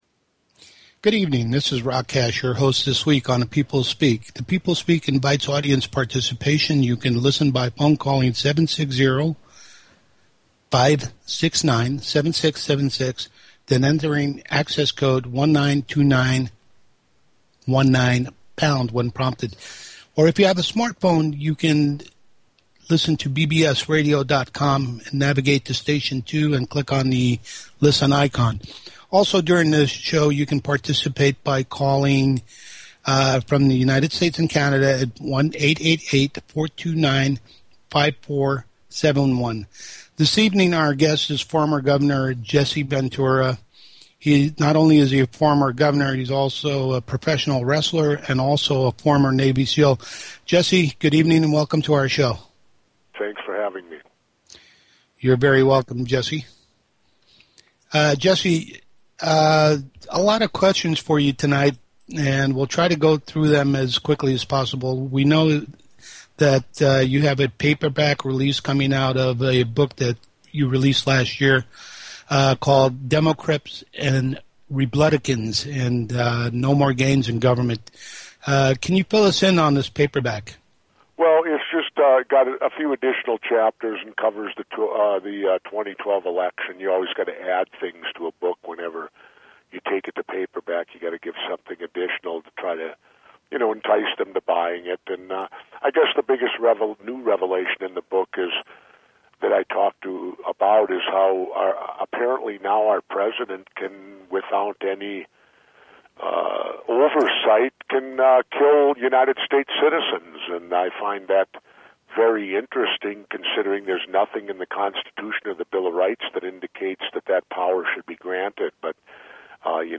Guest, Jesse Ventura